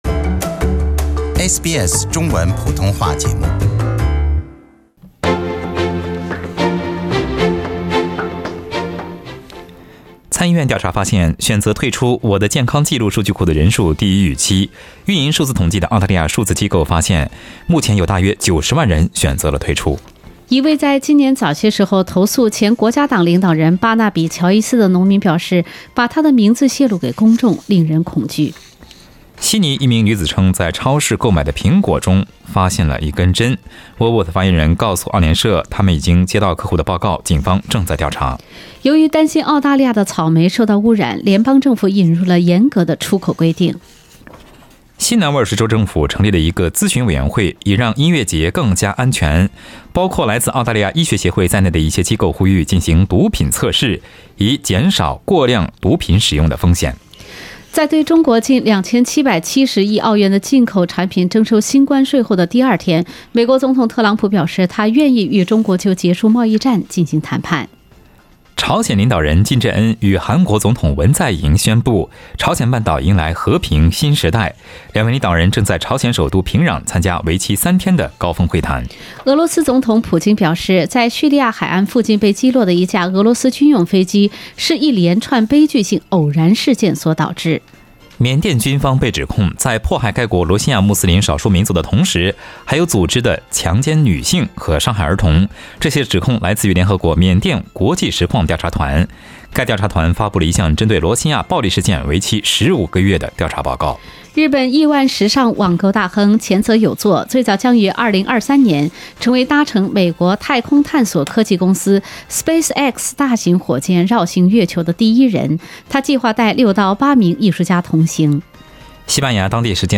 在本期的《正在行动》热线讨论节目中，听众朋友发表了不同的看法。一位养老业从业者打入电话称，自己在养老业工作中所看到的让自己不敢去养老机构养老。